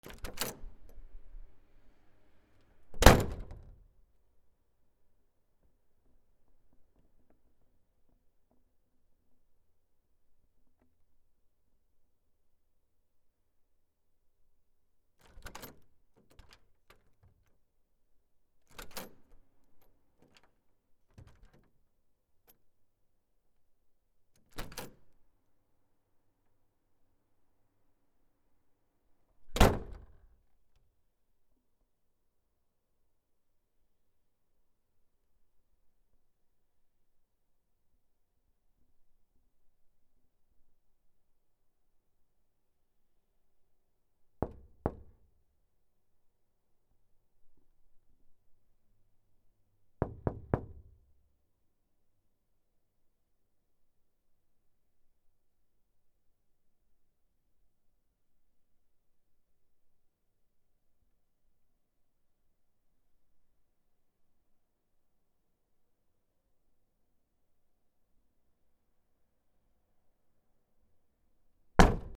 扉
/ K｜フォーリー(開閉) / K05 ｜ドア(扉)